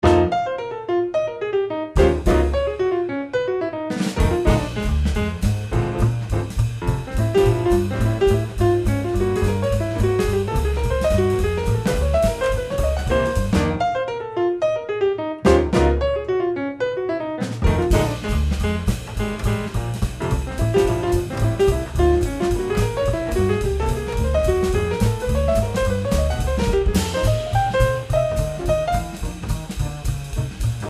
piano
batteria, percussioni
registrato ai Millennium Studio di Roma